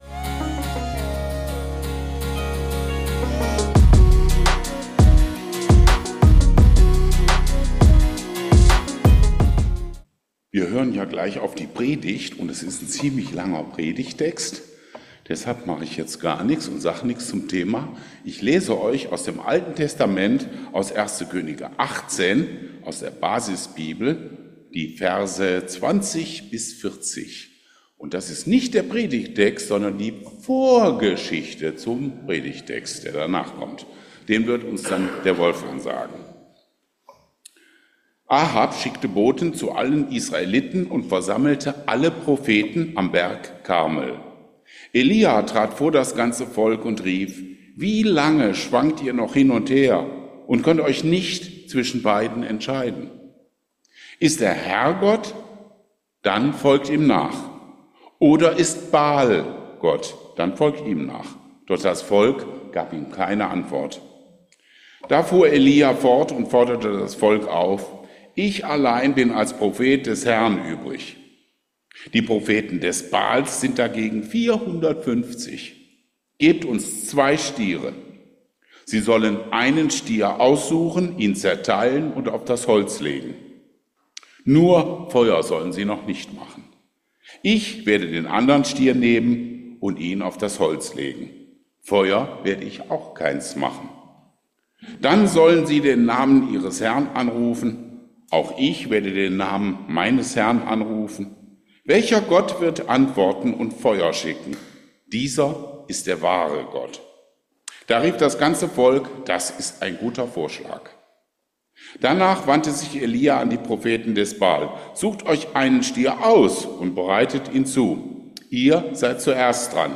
In gewohnt tiefgründiger Art - mit Humor gewürzt - sprach er über die Aufträge Gottes an seine Leute am Beispiel des Propheten Elia